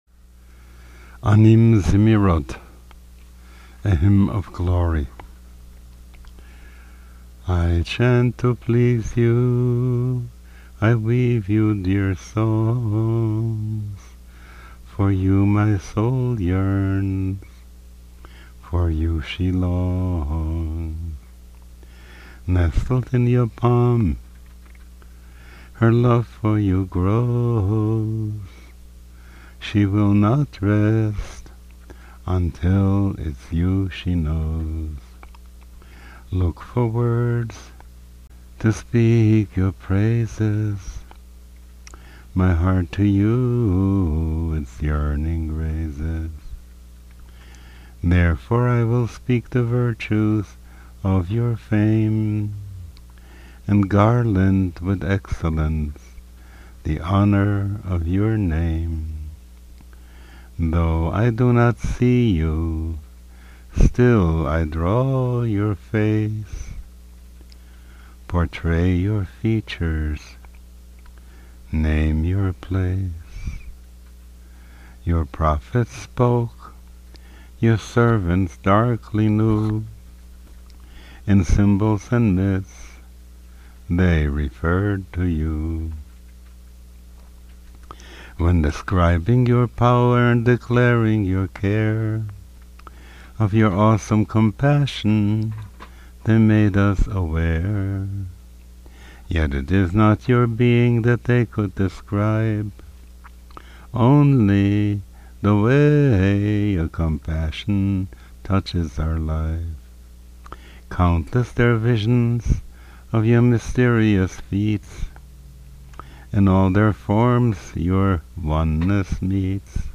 These recordings were not done in the studio under perfect conditions with sophisticated microphones and complicated mixers.  They were made over Skype using a Skype recorder and edited using a simple audio editor.